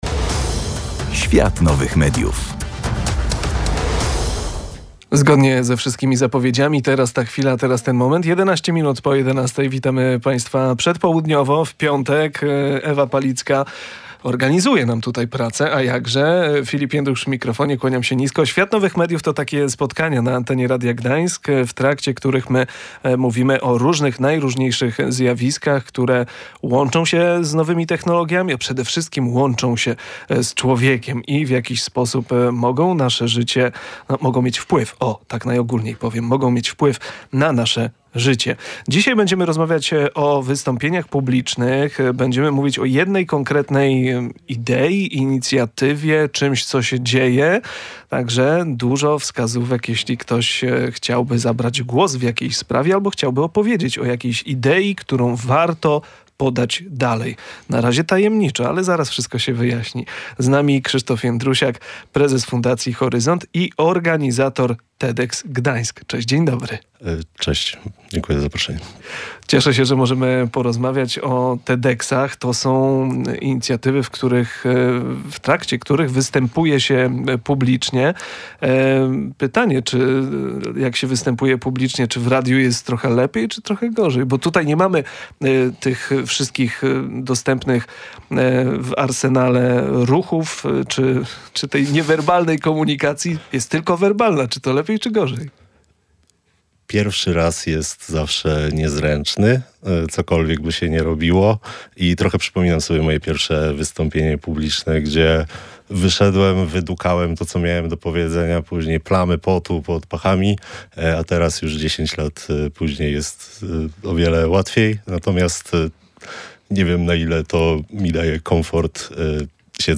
W kolejnym wydaniu audycji „Świat Nowych Mediów” mówiliśmy o wystąpieniach publicznych i inicjatywie poświęconej właśnie tego typu prezentacjom.